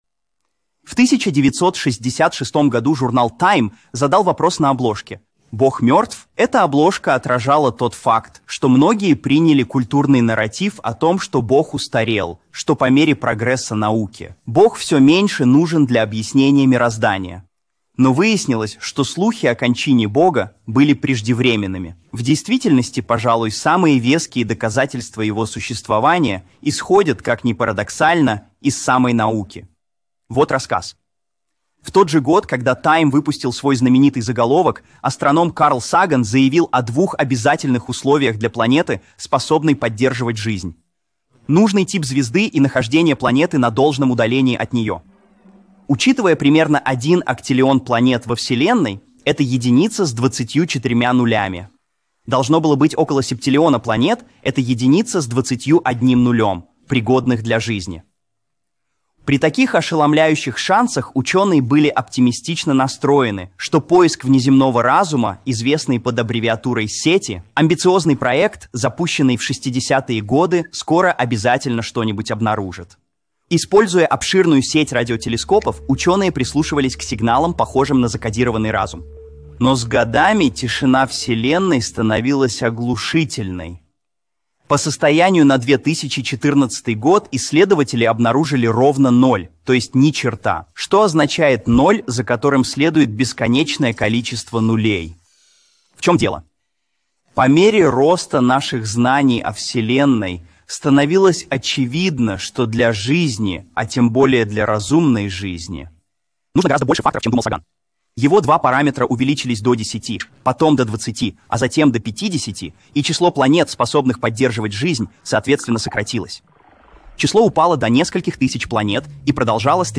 Описание: В этом видео Эрик Метаксас говорит о том, что многие известные атеисты по всему миру приводят доводы в пользу существования Бога.